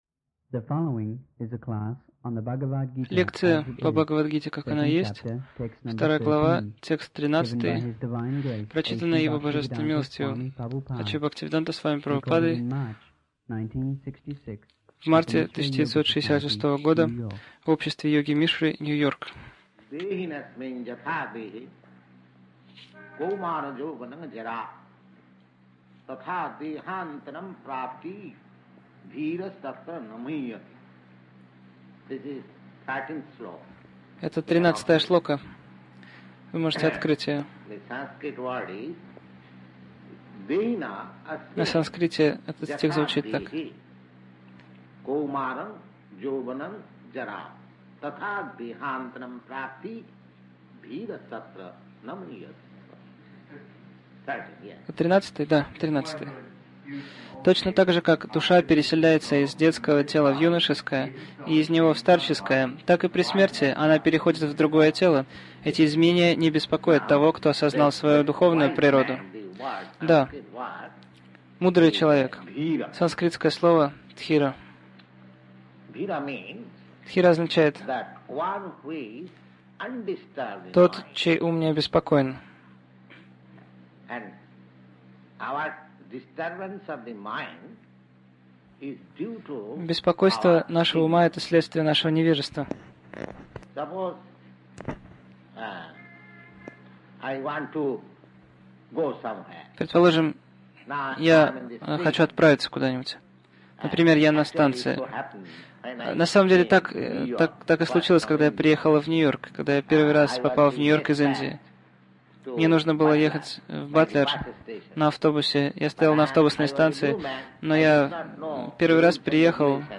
Милость Прабхупады Аудиолекции и книги 20.03.1966 Бхагавад Гита | Нью-Йорк БГ 02.13 Загрузка...